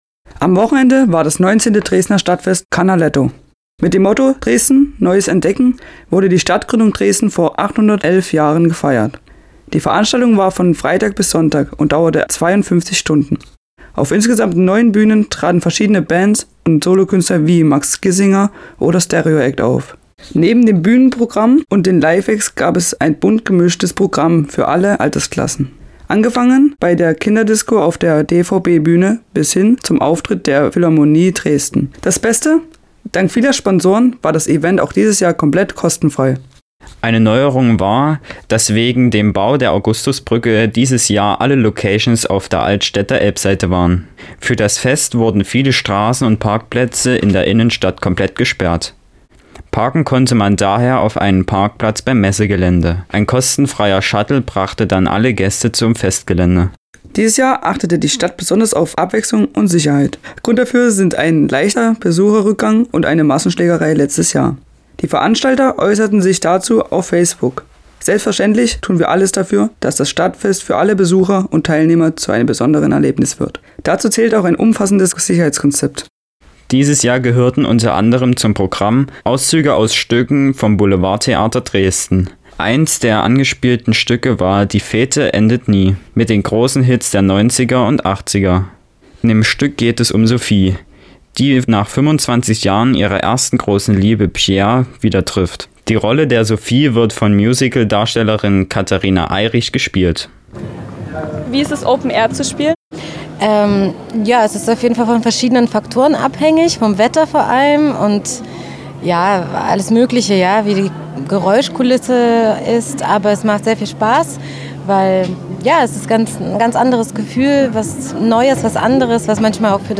Eine der Schauspielerinnen des Theaters hat sich zwischen den Auftritten Zeit für ein Interview genommen.